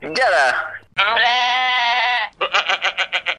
Index of /fastdownload/r_animals/files/goat